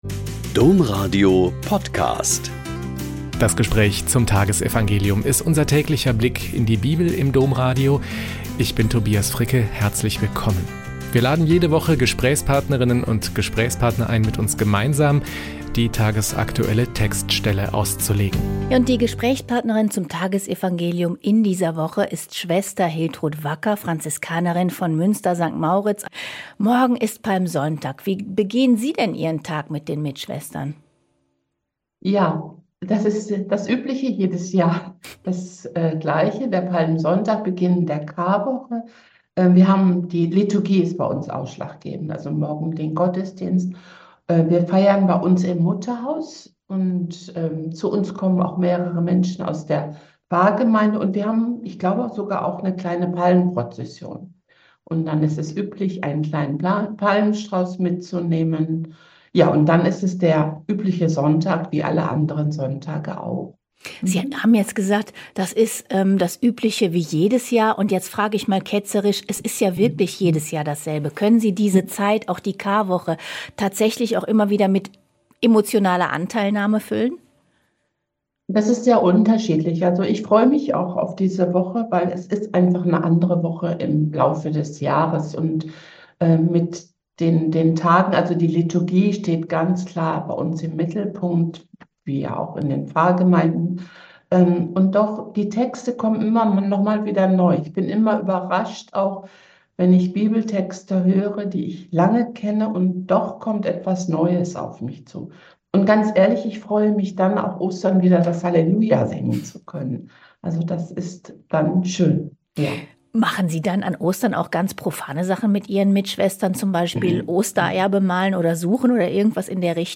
Joh 11,45-57 - Gespräch